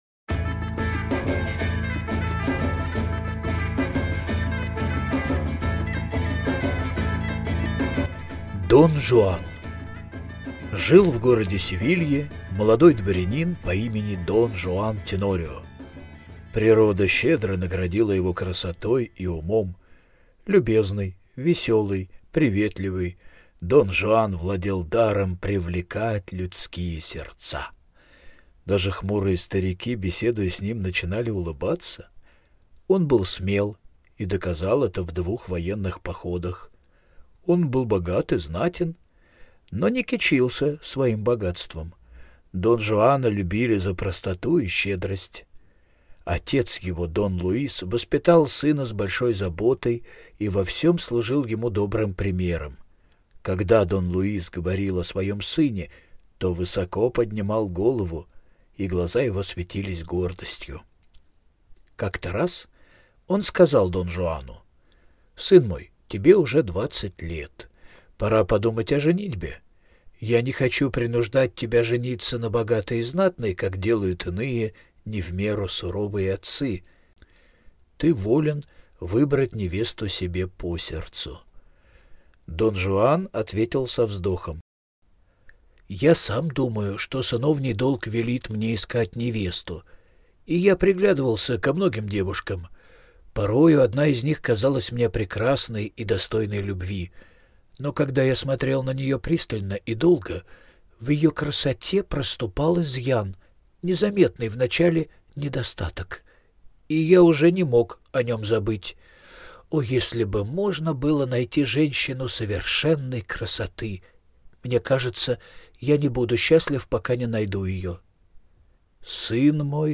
Дон Жуан - аудио легенда Европы - слушать онлайн